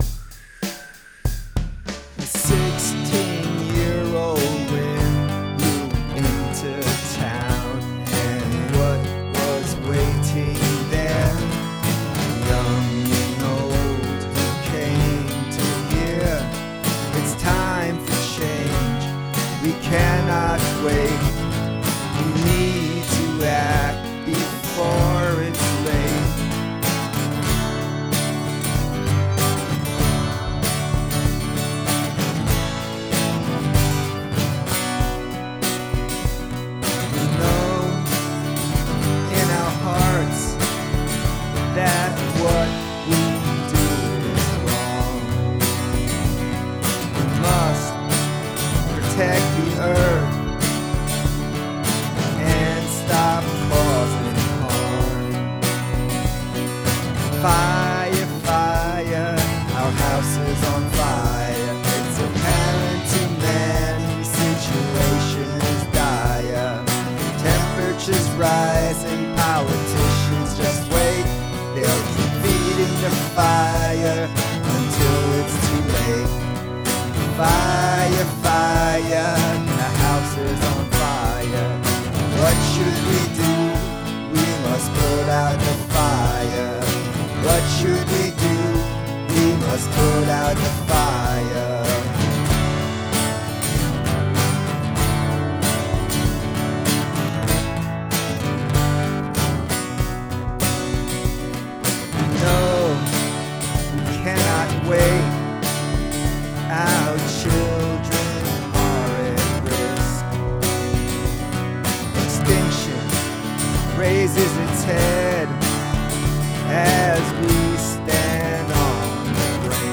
This is just a demo, a rough cut version of the song.
The House is on Fire, Spoken Word Version